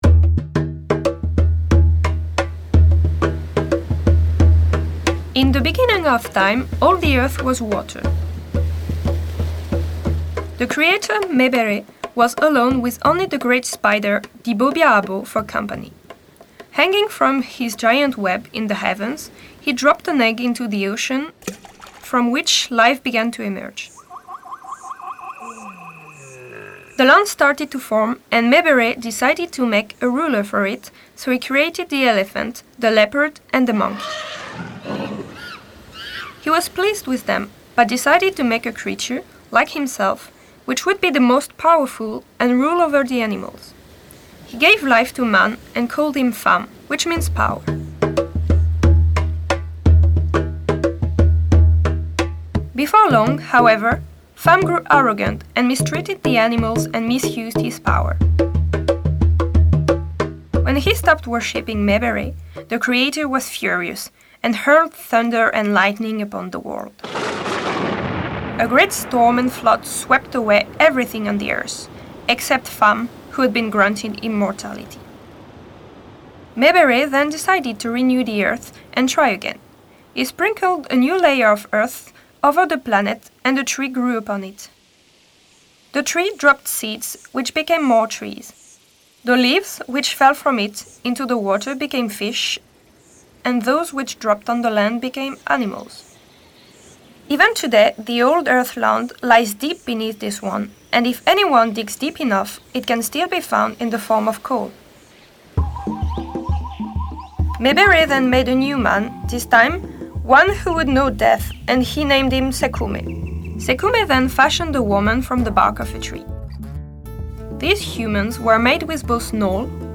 This traditional creation myth from Gabon is told in English by a teenage girl, accompanied by music and sound effects.